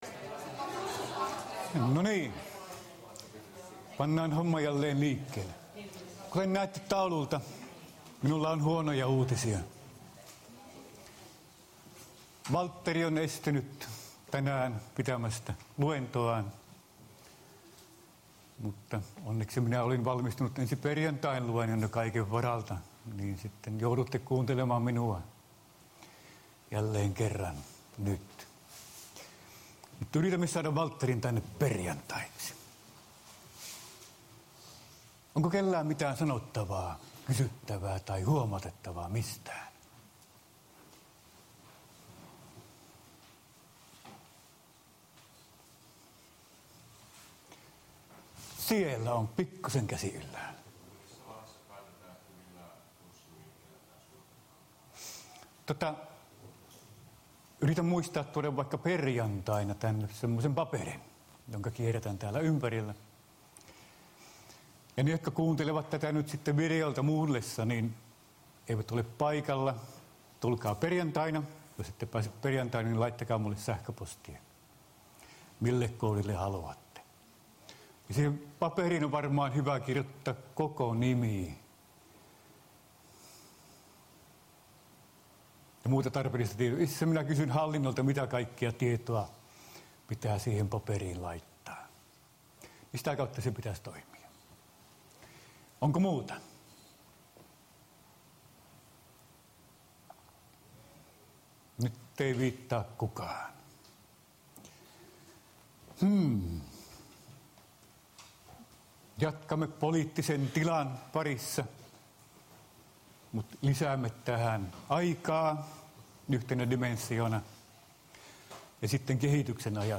POLS3017 Luento 5